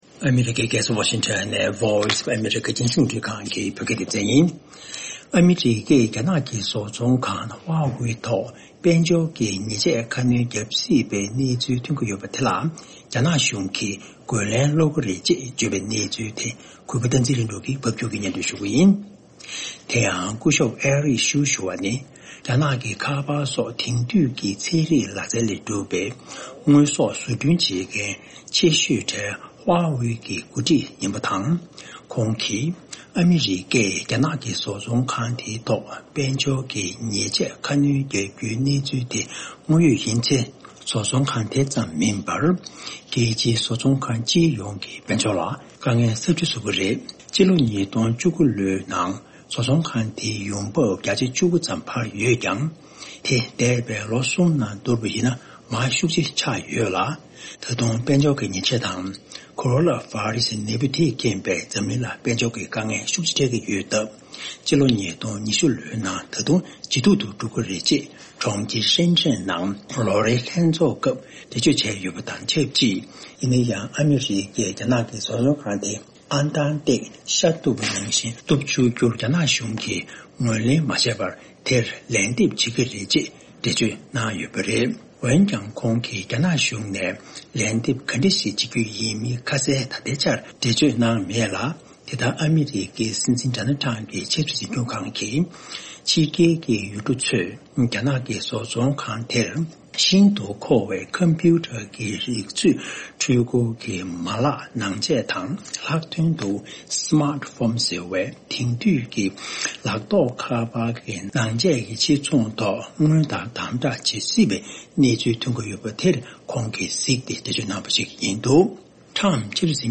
ཕབ་སྒྱུར་དང་སྙན་སྒྲོན་ཞུ་གནང་གི་རེད།